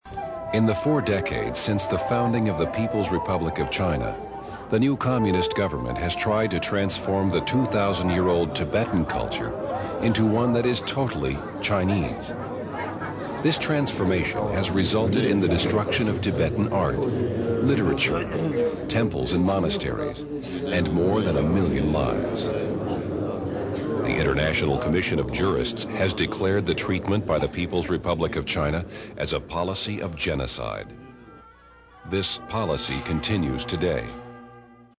Male Voice Overs